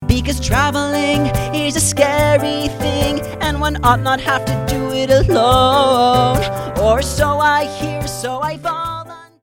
Here are some rough demos of a few of the songs: